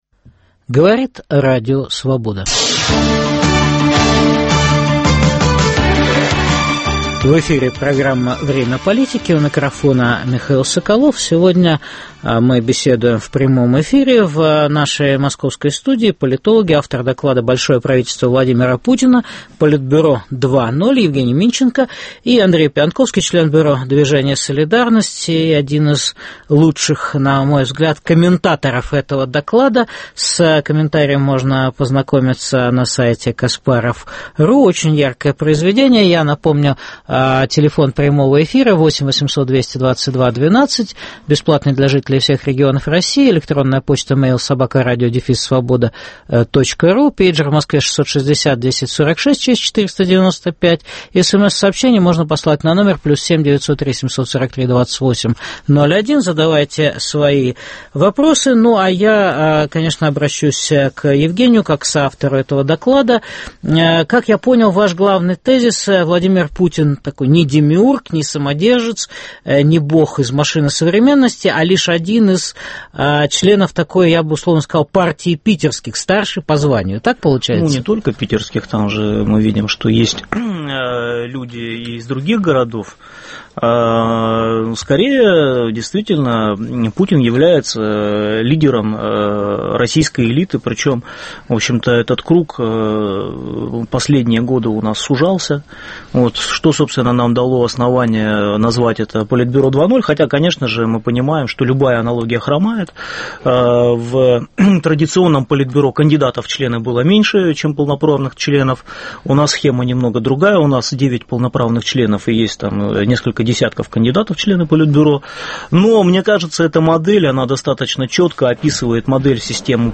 Кто и как правит Россией: "коллективное руководство" - "девятка" членов Политбюро 2.0 или единолично самодержец - глава клана? В программе в прямом эфире дискутируют политологи